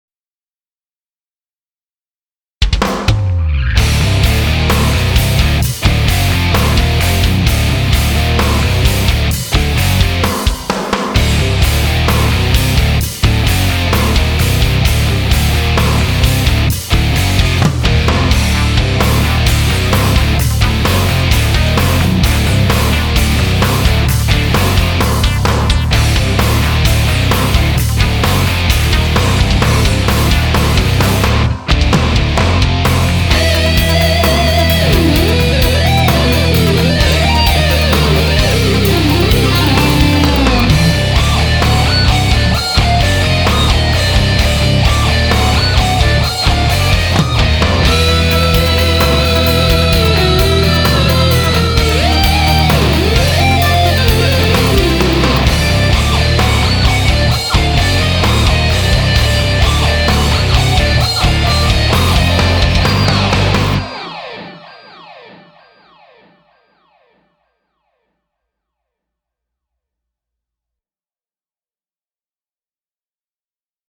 AMT E-Drive � Clean ����� SS-20 (��������� NEW Demo �� Cybertoyz!)